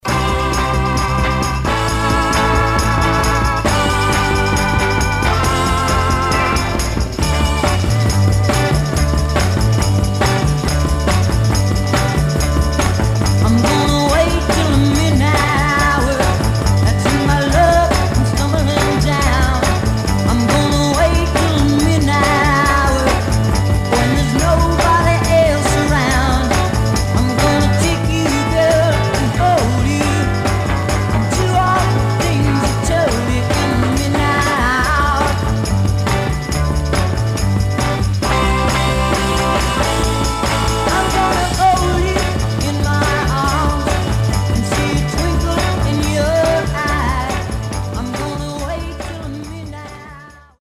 Some surface noise/wear
Mono
Garage, 60's Punk